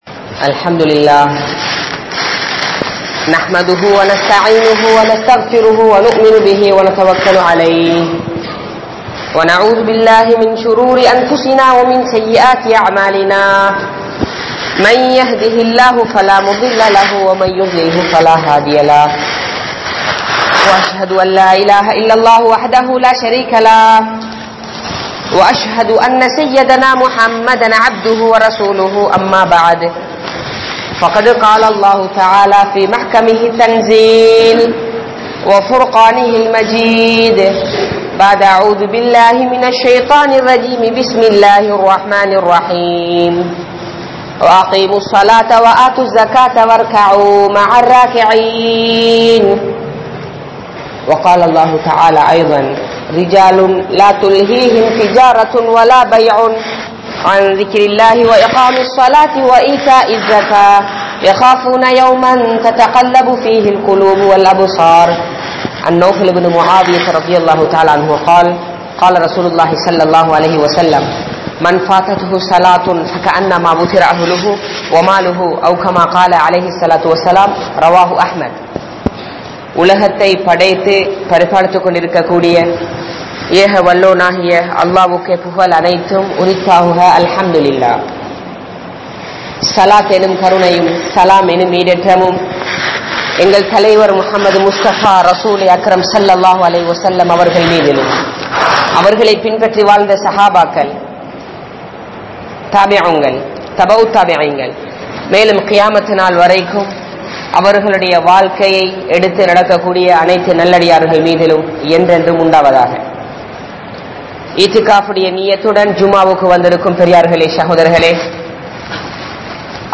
Jamaath Tholuhaien Sirappuhal (ஜமாஆத் தொழுகையின் சிறப்புகள்) | Audio Bayans | All Ceylon Muslim Youth Community | Addalaichenai
Grand Jumua Masjidh(Markaz)